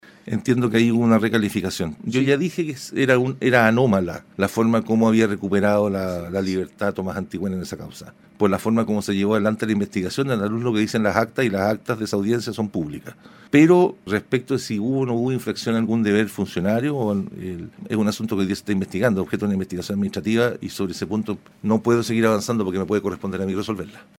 La información la entregó el fiscal nacional, Ángel Valencia, en entrevista con Radio Bío Bío, insistiendo además en la extraña forma como el comunero recuperó la libertad en la indagatoria por el ataque incendiario contra una patrulla policial.